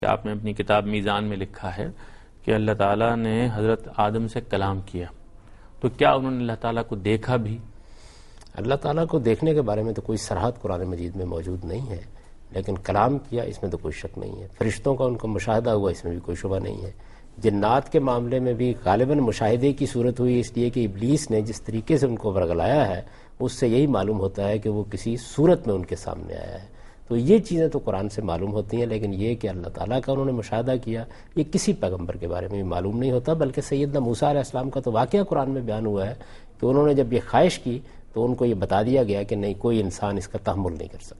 TV Programs
Answer to a Question by Javed Ahmad Ghamidi during a talk show "Deen o Danish" on Dunya News TV